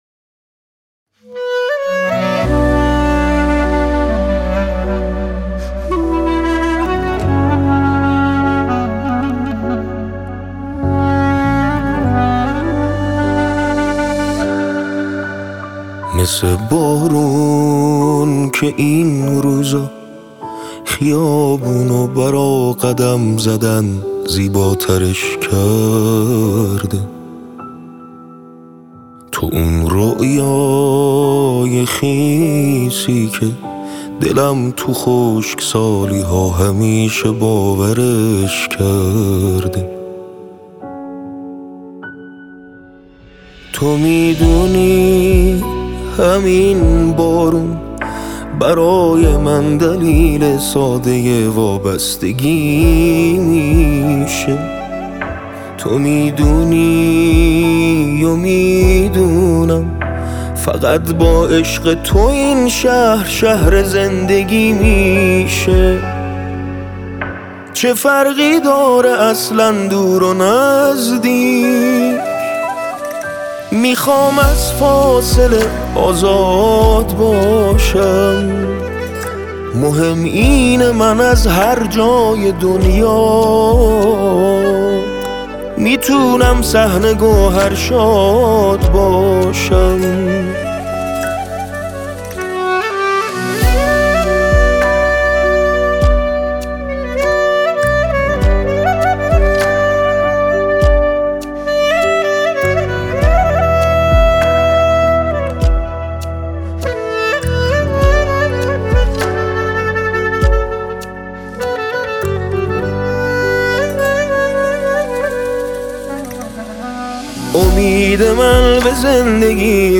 نماهنگ